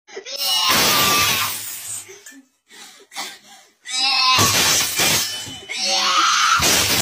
person throws up silverware and sound effects free download